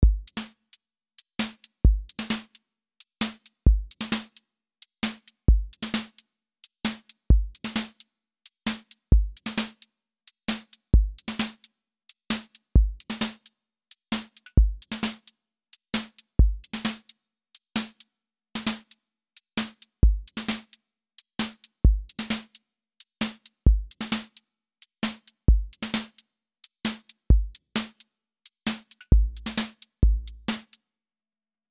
QA Listening Test contemporary-rap Template: contemporary_rap_808
808-led contemporary rap beat with wide vocal space, moody melody loop, and a clean verse-to-hook lift
• voice_kick_808
• voice_snare_boom_bap
• voice_hat_trap
• tone_warm_body